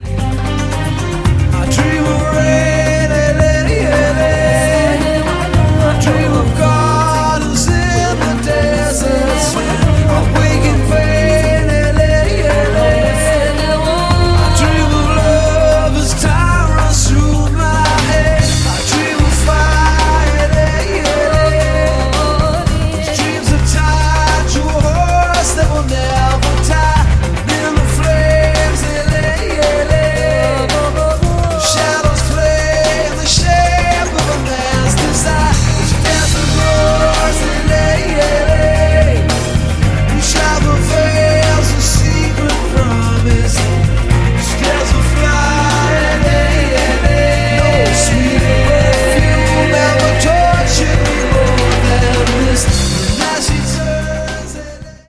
• Качество: 256, Stereo
восточные
Pop Rock
романтичные
баллада
этнические